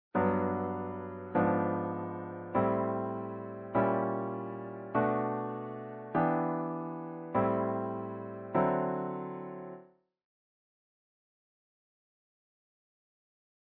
Sharp Two diminished / # ii diminished 7th. In this next idea, we simply slip in a diminished chord between our diatonic Two and Three in the major tonality. This is surely mostly a jazz thing as the passing chord seems to accelerates the overall motion of the progression.